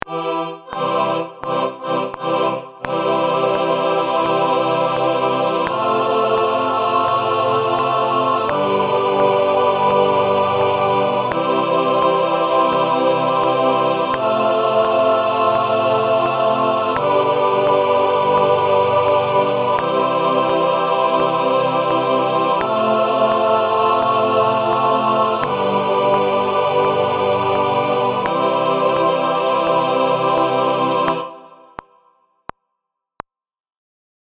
It can be beneficial to have a backing track that plays the progression, keeping students on track with the pitch and the timing.
You’ll hear each pitch (choose the one you’d like to start on) doubled at the octave followed by four clicks before the progression begins.
harmonic-improvisation-1.mp3